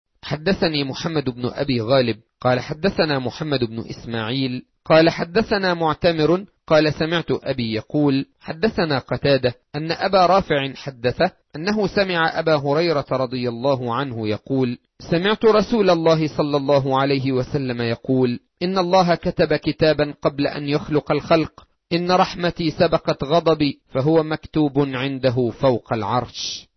الـكتب الناطقة باللغة العربية